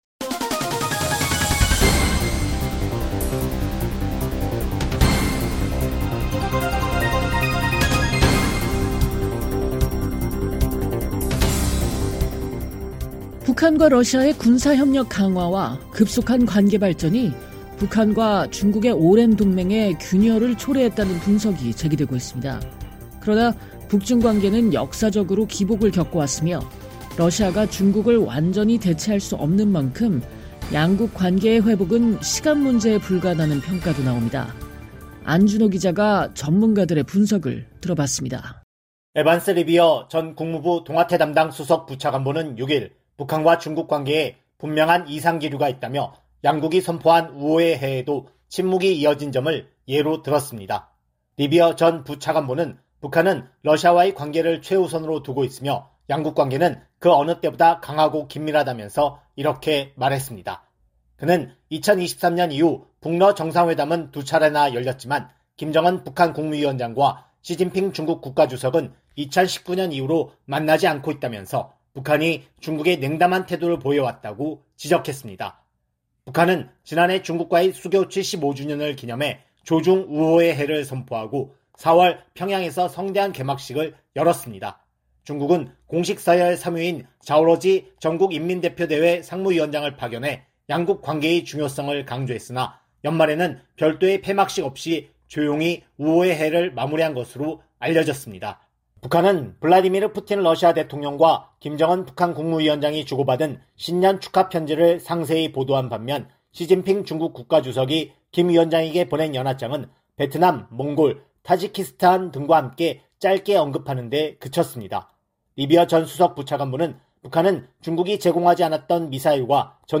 전문가들의 분석을 들어봤습니다.